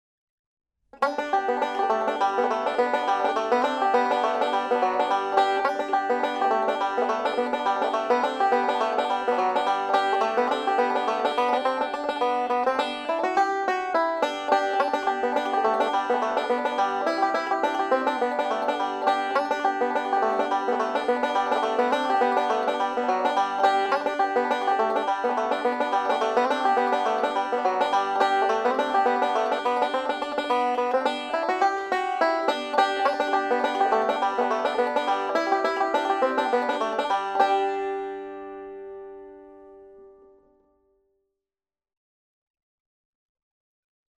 5-STRING BANJO SOLO Christmas
G Tuning
DIGITAL SHEET MUSIC - 5-STRING BANJO SOLO